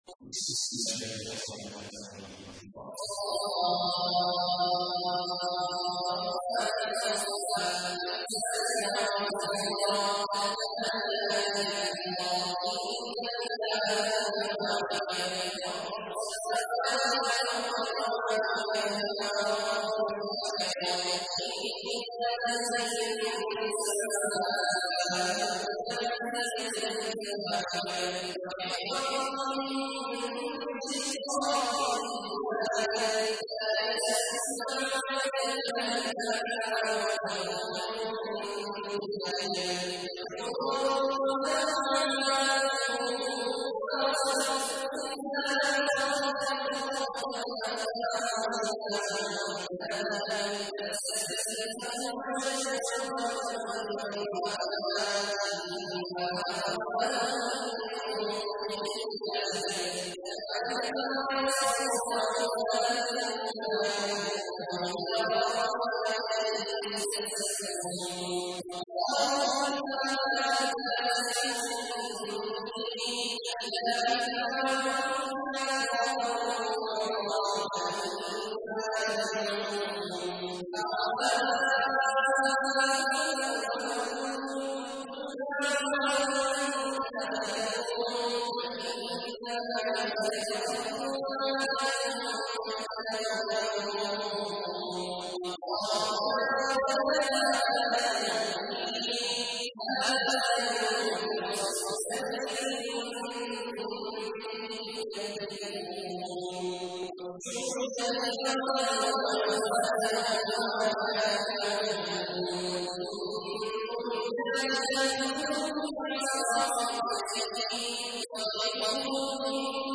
تحميل : 37. سورة الصافات / القارئ عبد الله عواد الجهني / القرآن الكريم / موقع يا حسين